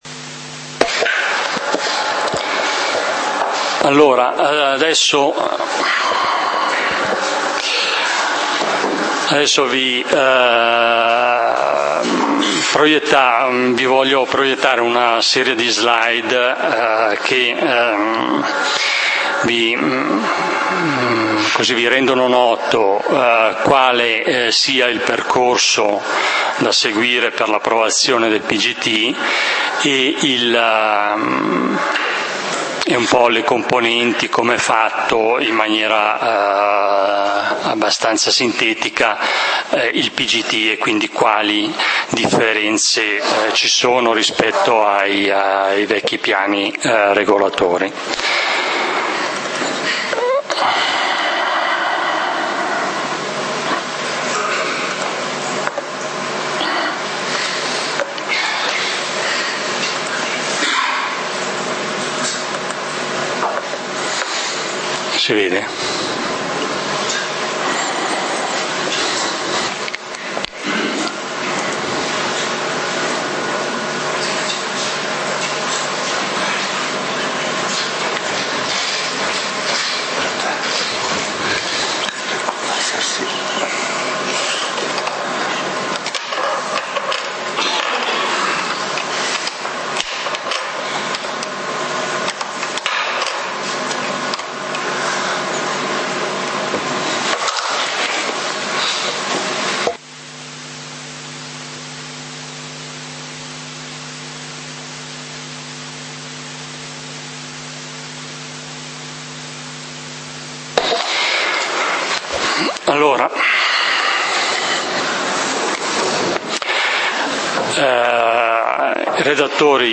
Assemblea pubblica del comunale di Valdidentro del 04 Ottobre 2013